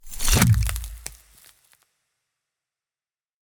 Impacts and Hits - Sub Zero 02.wav